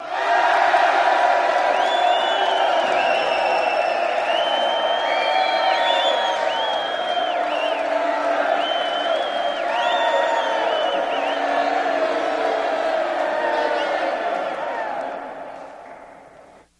Crowd Yeahs and Boos at end